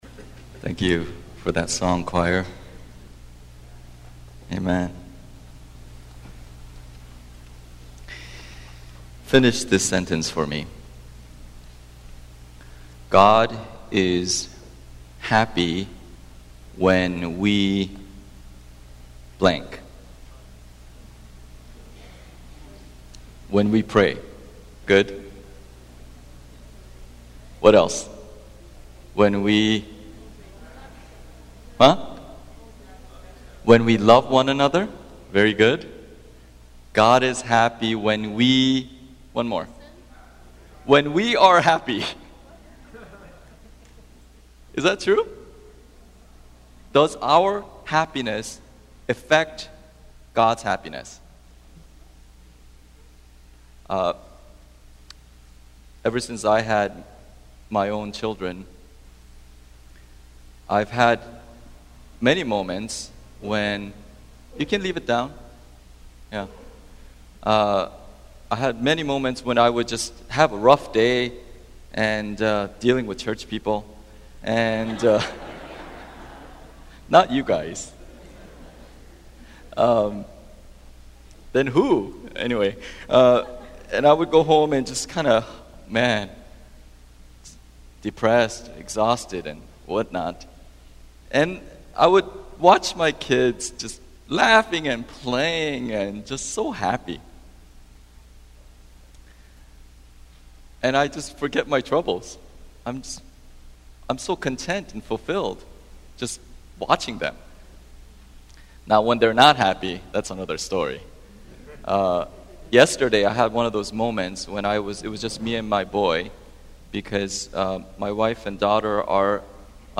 Sermons 2012